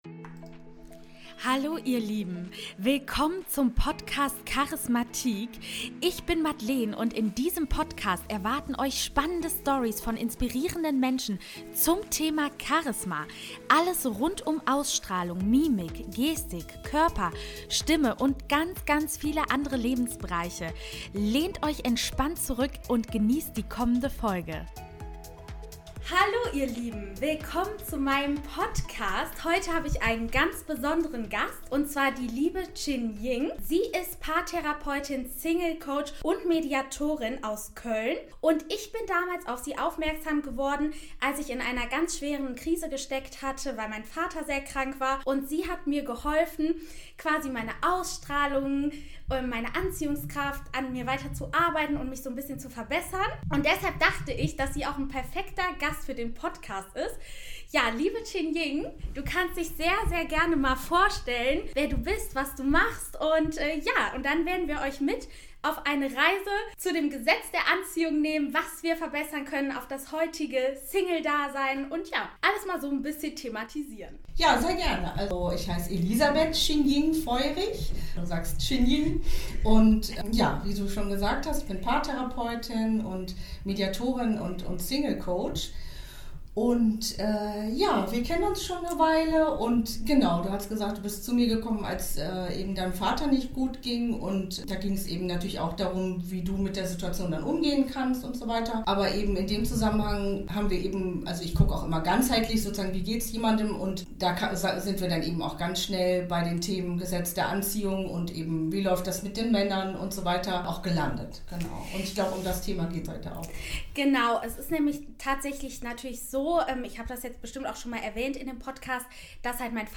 Das Gesetz der Anziehung und wie Dating heutzutage funktionieren kann - Interview